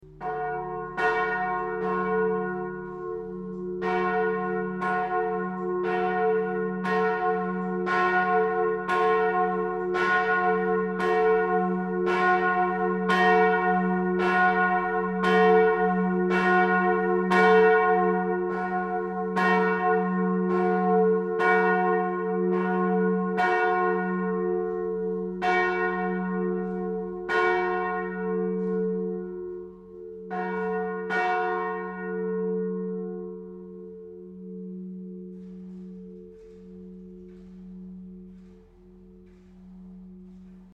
Die Glocken von Mondsee klingen so
Mondsee_Glocke_4_MP3